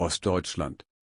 ost deutschland Meme Sound Effect